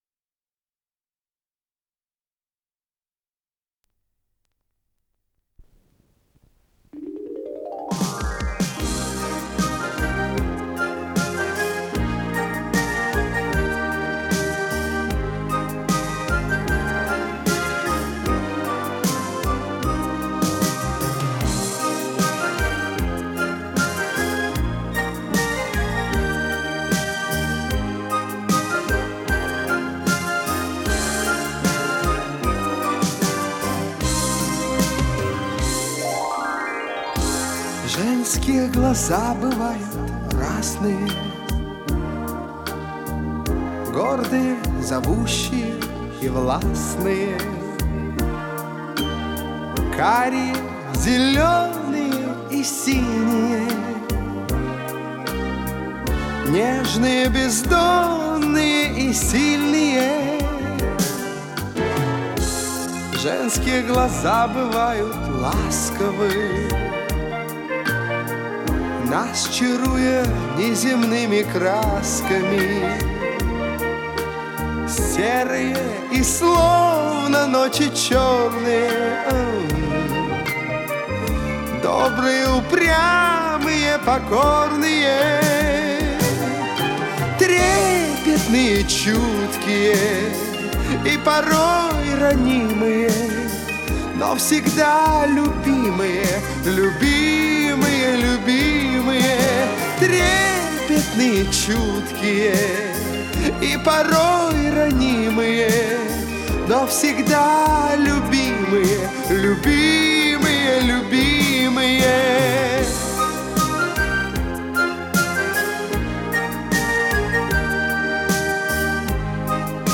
с профессиональной магнитной ленты
Скорость ленты38 см/с
МагнитофонМЭЗ-109А